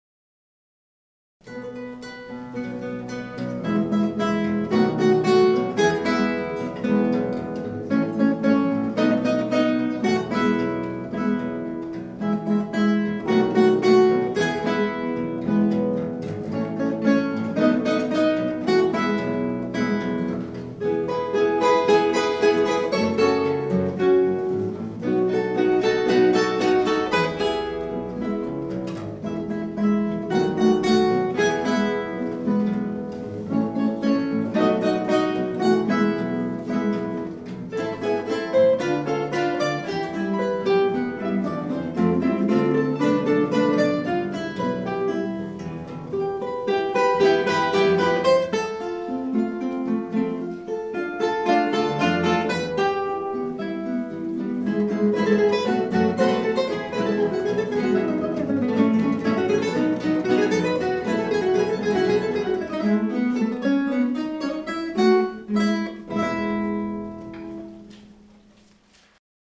• Kytarový orchestr
Hráči používají klasické kytary ve standardním ladění, 1/8 kytary se sopránovými oktávovými strunami a klasickou basovou kytaru se strunami laděnými o oktávu níže, popř. sólovou elektrickou kytaru (u úprav rockových skladeb). Do některých skladeb bývají také zapojeny perkusní či Orffovy nástroje.